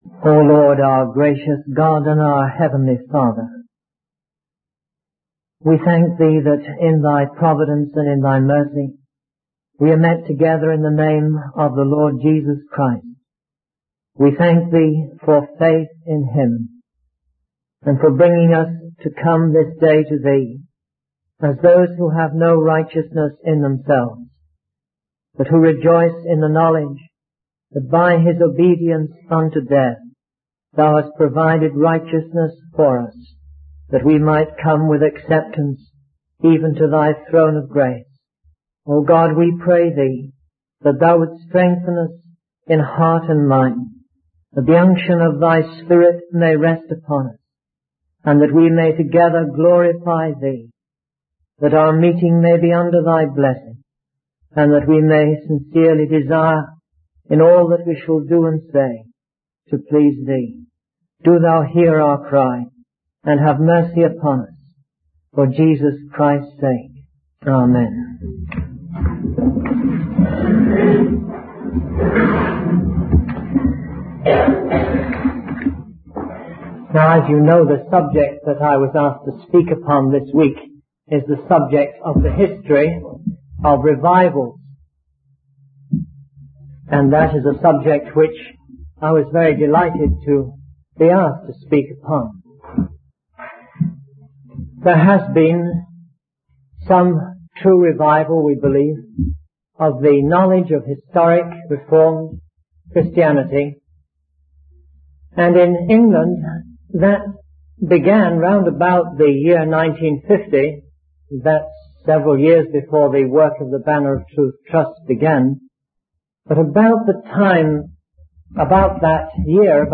In this sermon, the preacher discusses the common features observed in revivals. One of these features is a great hunger for the word of God, where people cannot hear it preached enough.